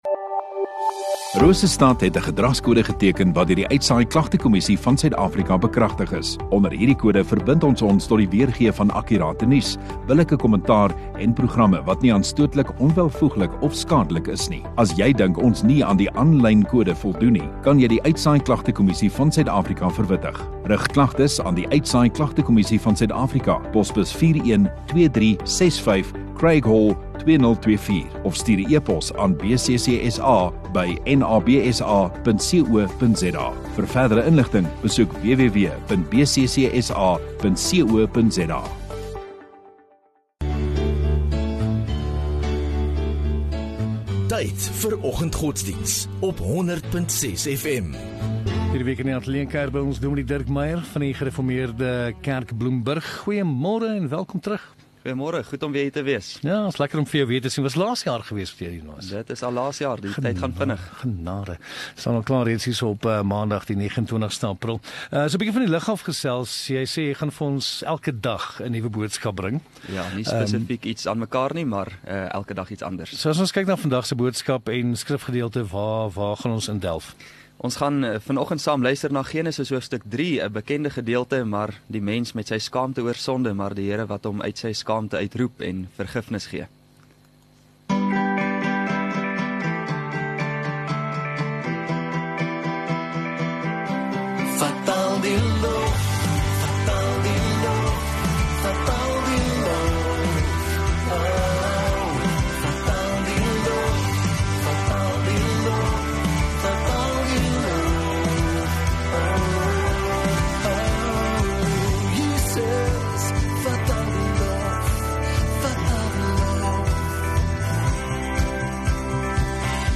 29 Apr Maandag Oggenddiens